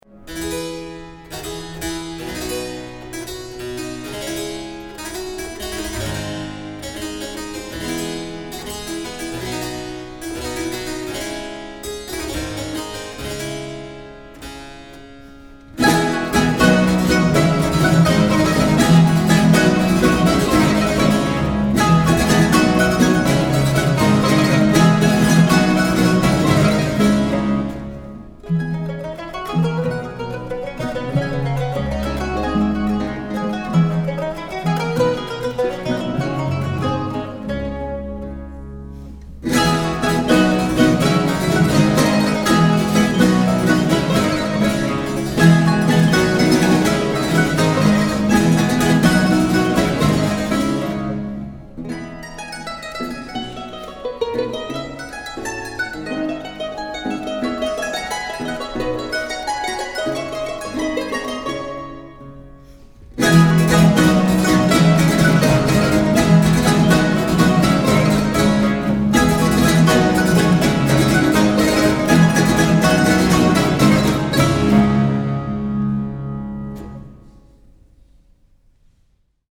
mandoline & mandoline baroque
guitare baroque
viole de gambe
clavecin